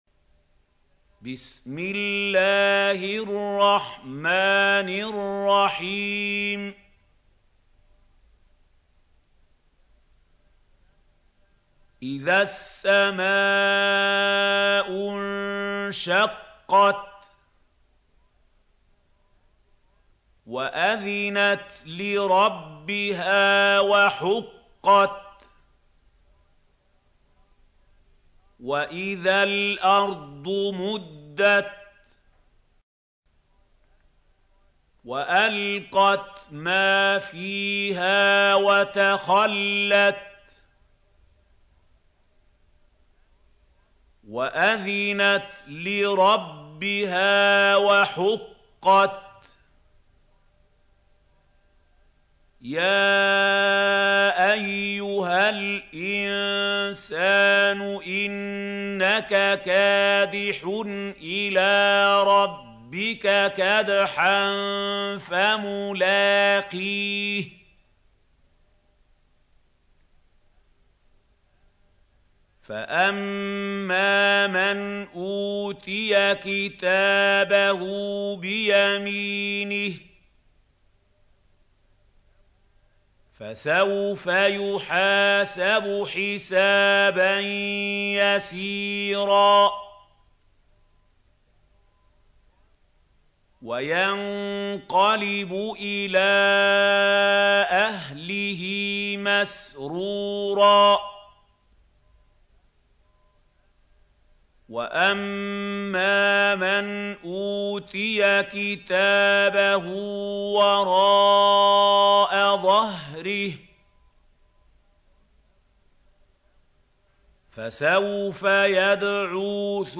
سورة الانشقاق | القارئ محمود خليل الحصري - المصحف المعلم